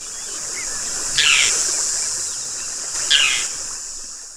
Gull-billed Tern
Sterna nilotica
VOZ: Un llamado consistiendo de dos notas.